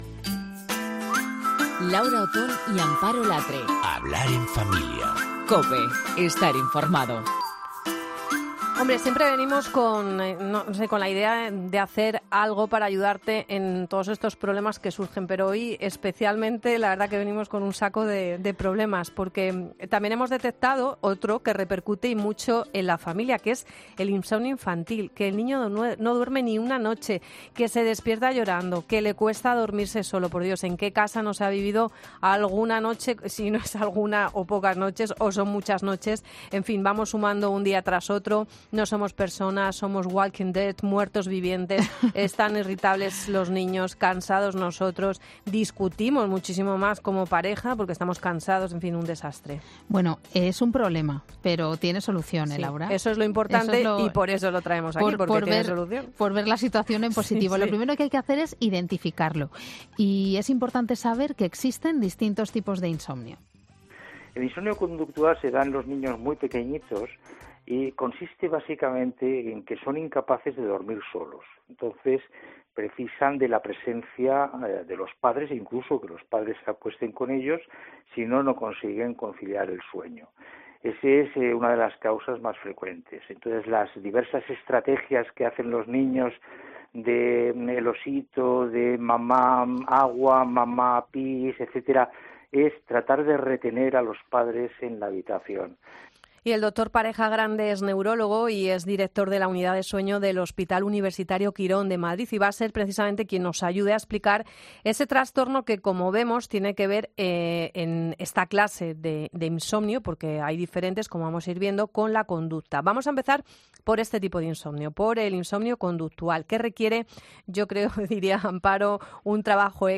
Si quieres saber cómo se atajan y resuelven estos problemas el doctor nos lo cuenta en estos minutos de radio.